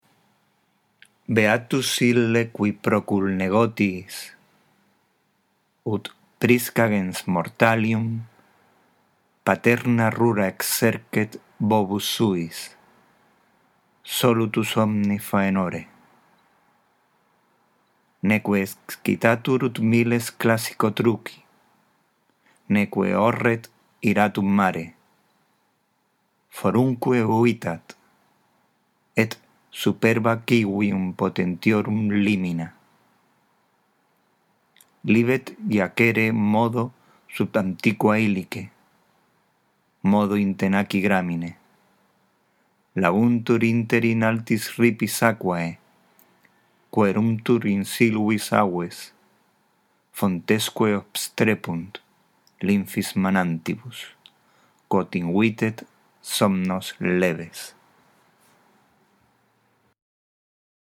Para que tu lectura del latín mejore te sugerimos que escuches esta grabación antes de hacer tu propia lectura.